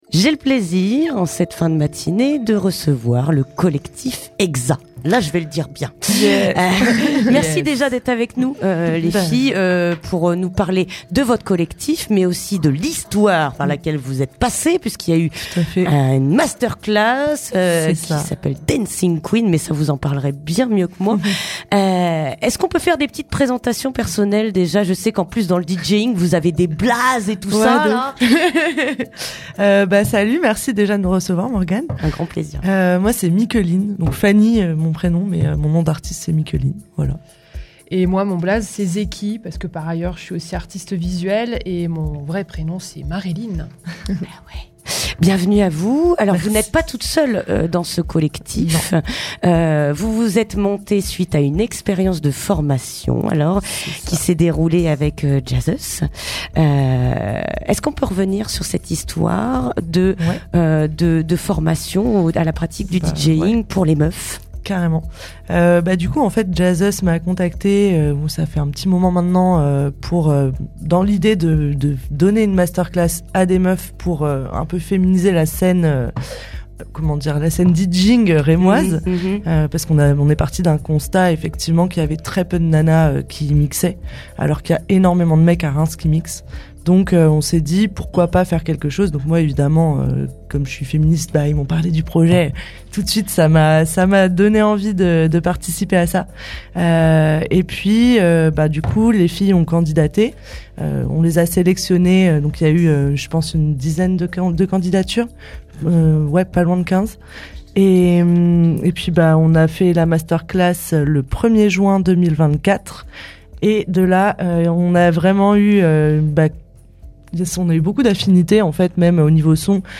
Interview du collectif Hexa (15:39)